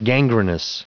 Prononciation du mot gangrenous en anglais (fichier audio)
Prononciation du mot : gangrenous